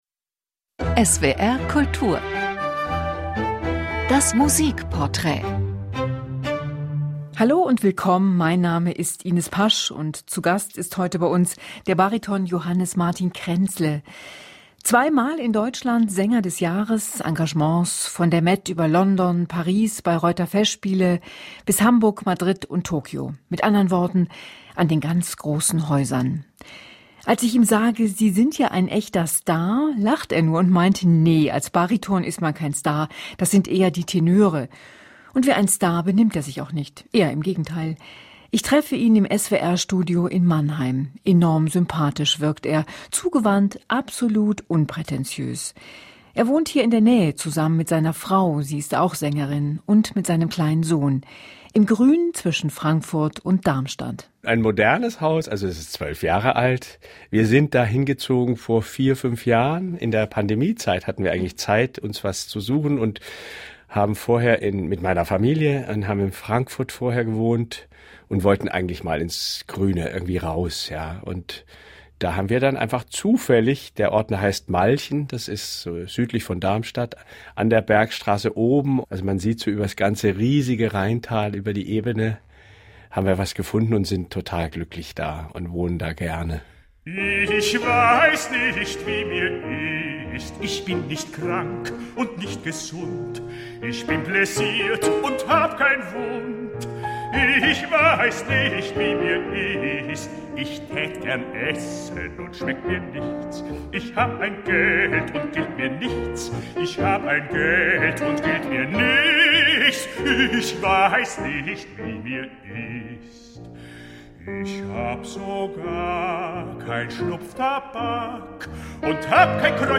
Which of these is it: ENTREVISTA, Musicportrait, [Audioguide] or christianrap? Musicportrait